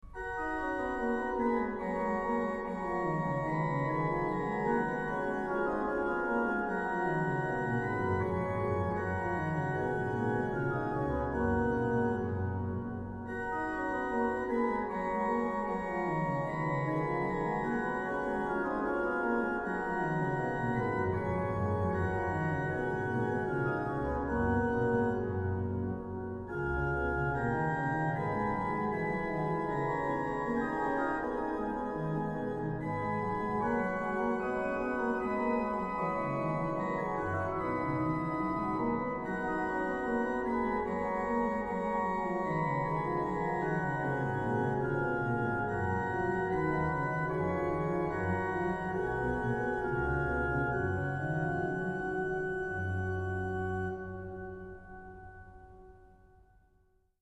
Literatuur en improvisaties (St. Stevenskerk te Nijmegen)